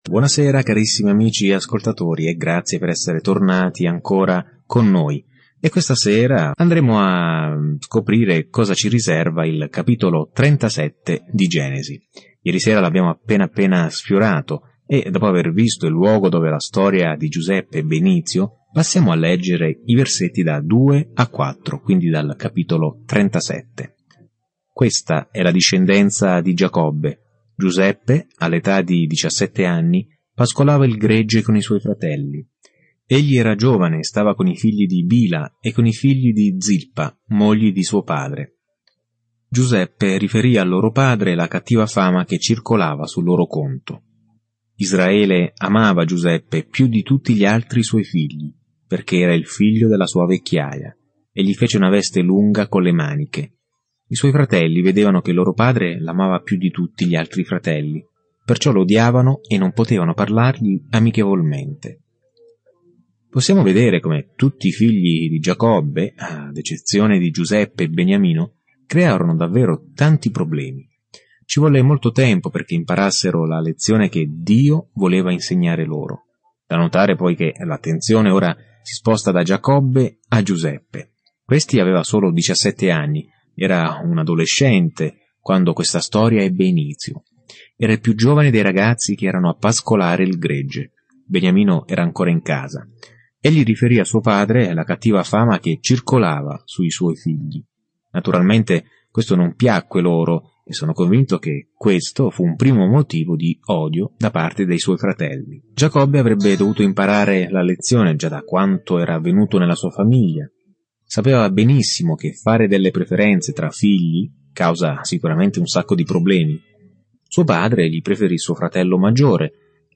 Scrittura Genesi 37:2-24 Giorno 37 Inizia questo Piano Giorno 39 Riguardo questo Piano È qui che tutto comincia: l’universo, il sole e la luna, le persone, le relazioni, il peccato, tutto. Viaggia ogni giorno attraverso la Genesi mentre ascolti lo studio audio e leggi versetti selezionati dalla parola di Dio nel libro della Genesi.